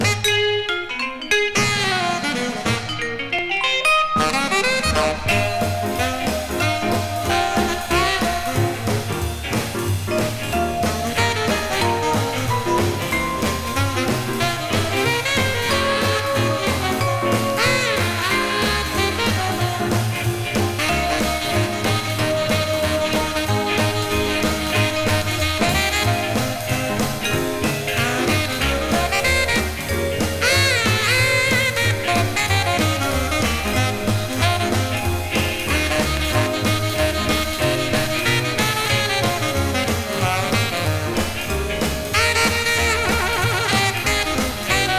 熱さも涼しも感じるような、洗練と洒落気たっぷり。
Jazz, Rhythm & Blues　USA　12inchレコード　33rpm　Stereo